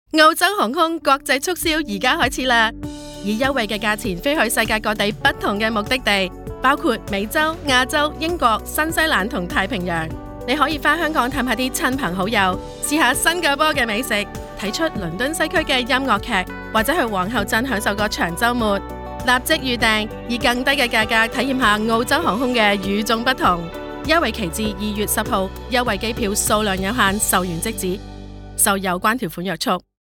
Female
My voice has been described as friendly, trustworthy, convincing, authoritative, authentic, enthusiastic, enticing, cut from a different cloth with a sassy certainty by my clients and peers.
Radio Commercials